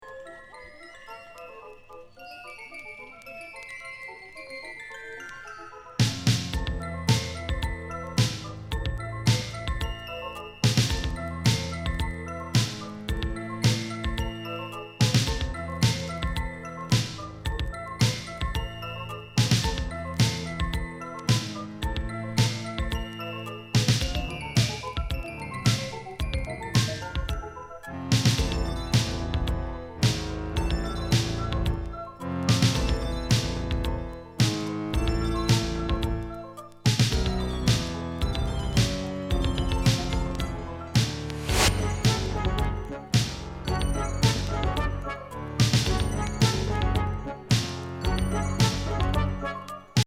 中世ミサ～ゴシック的な
不穏ムード・ブレイク